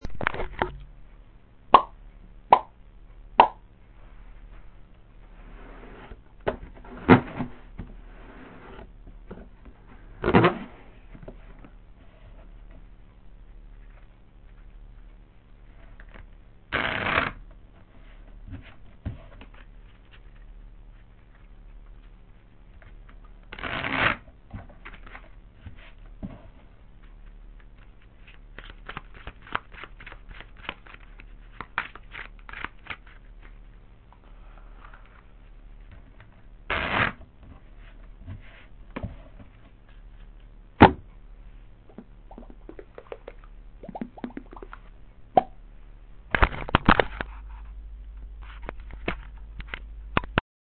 For groups of people, an audio collaboration is encouraged. full instructions Typical noises that you might hear from my room on a Sunday evening...